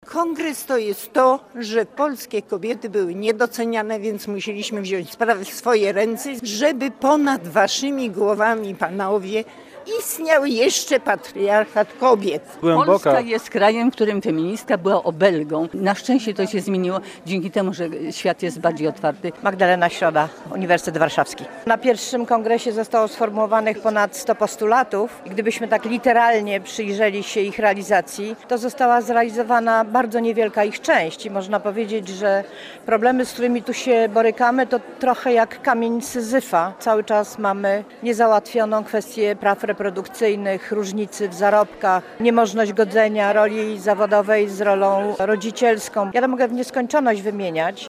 Trwa Kongres Kobiet w Trójmieście. Nadawaliśmy na żywo z Ergo Areny
Na miejscu stanęło także studio mobilne Radia Gdańsk.